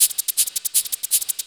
Shaker 04.wav